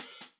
TM88 StyleOpen-Hat.wav